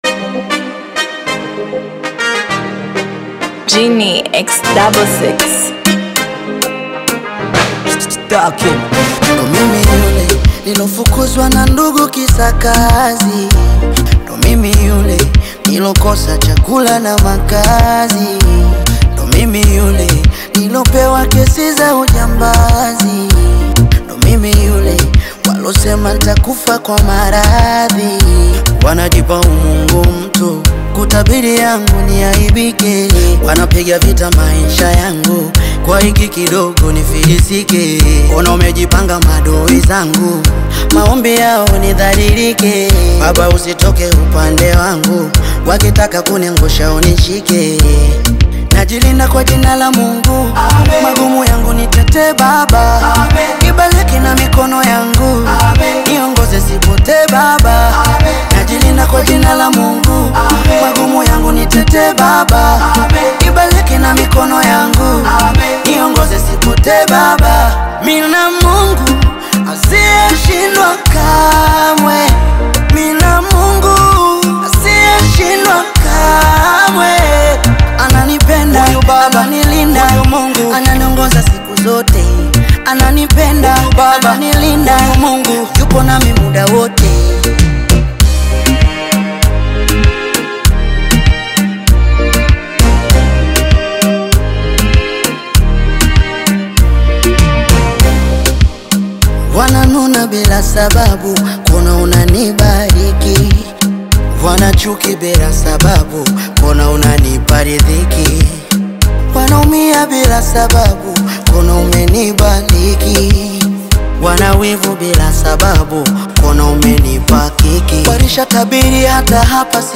gospel
uplifting and faith-filled song